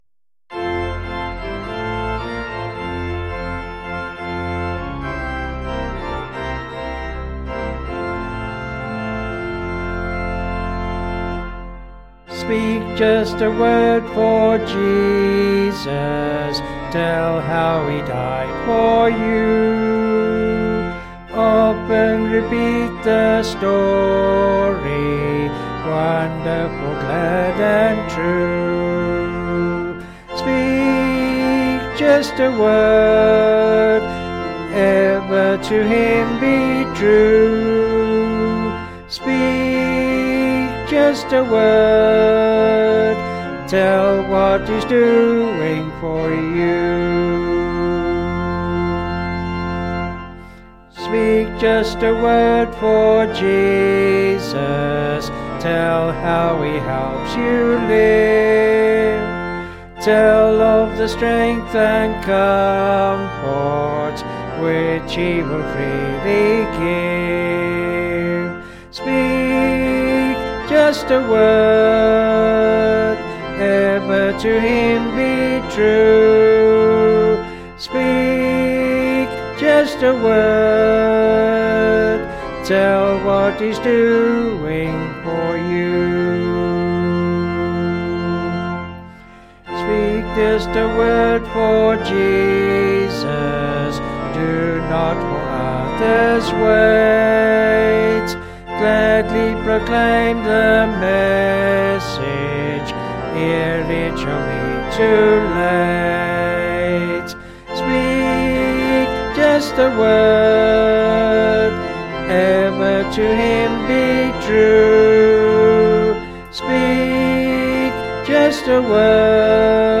Vocals and Organ   264.3kb Sung Lyrics